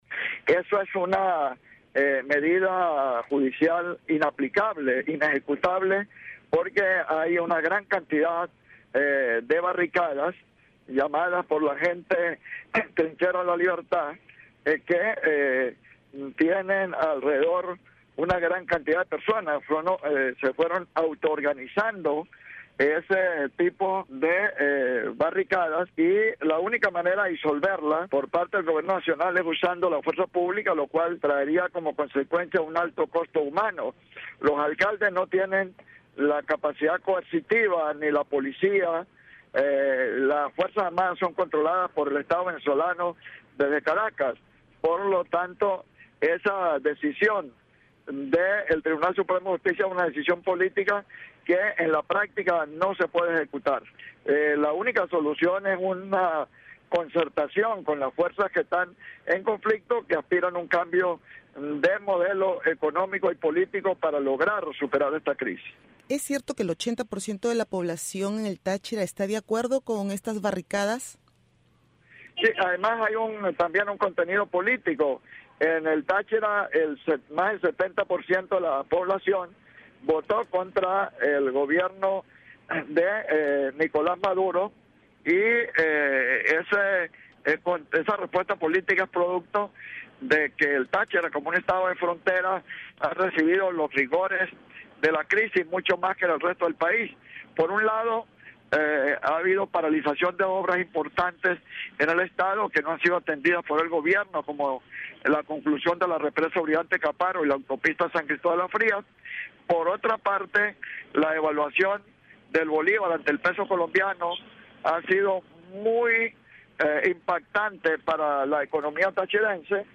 Entrevista al diputado de Táchira Walter Márquez